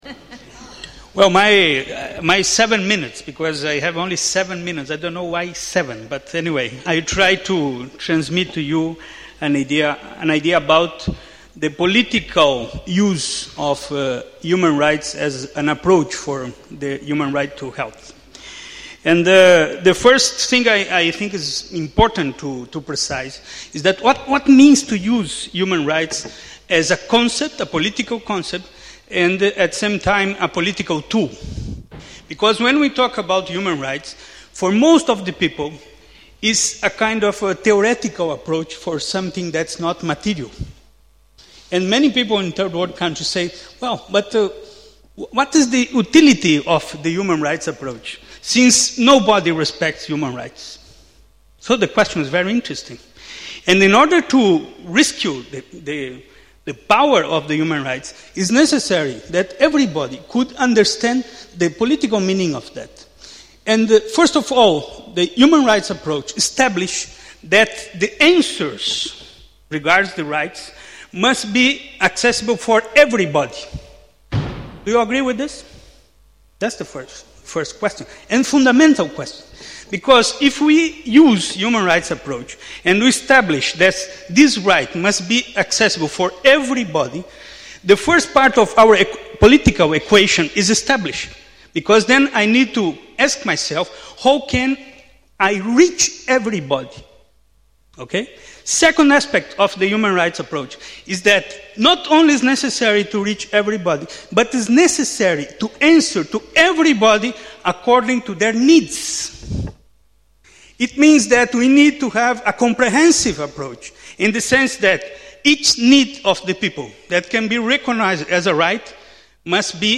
Dokumentation: Konferenz "global, gerecht, gesund" 2010 - medico international